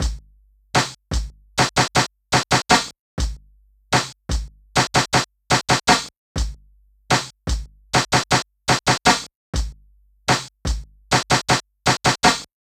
Bimmer Drumloop.wav